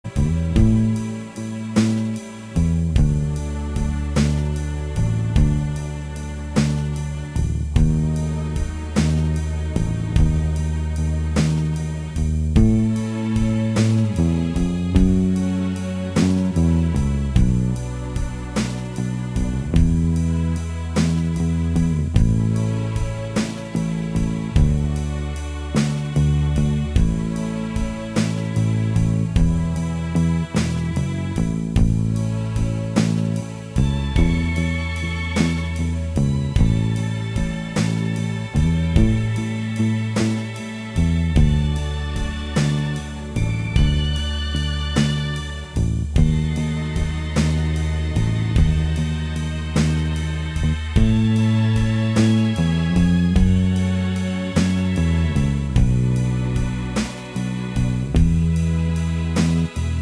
Minus All Guitars For Guitarists 3:55 Buy £1.50